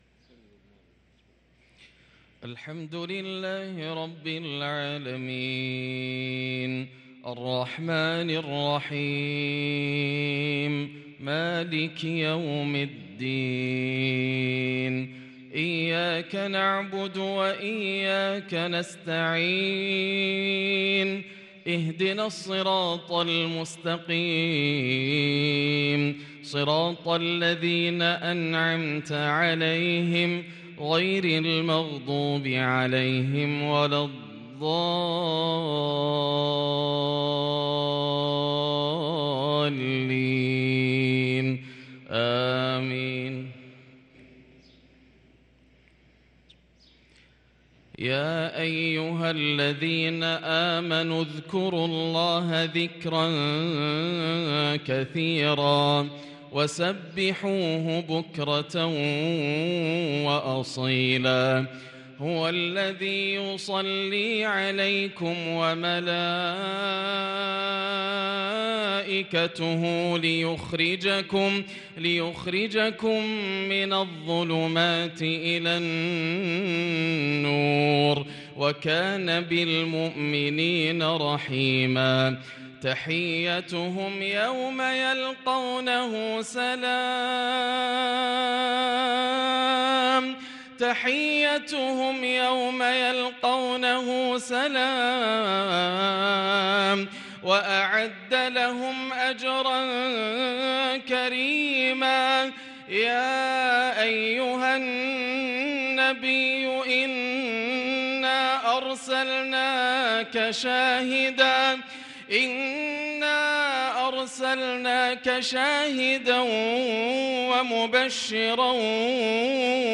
صلاة المغرب للقارئ ياسر الدوسري 9 ربيع الآخر 1444 هـ
تِلَاوَات الْحَرَمَيْن .